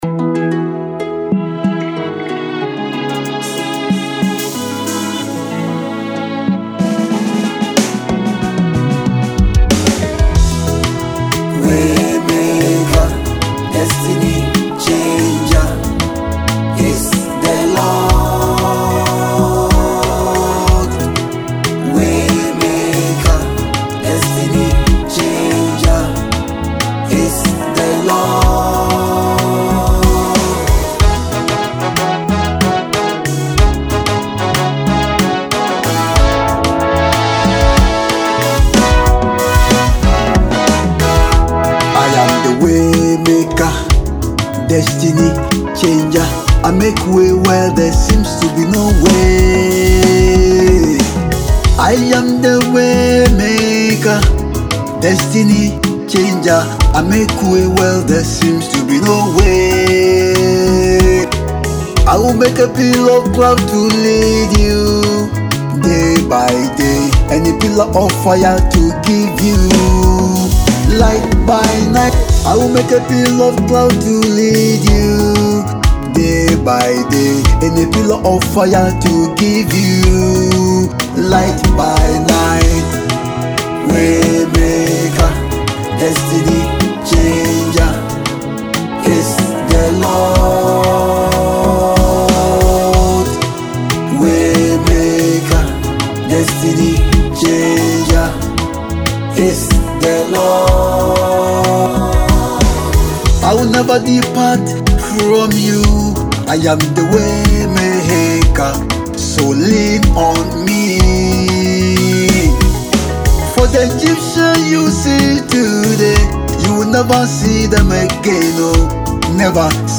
gospel song
This emotive track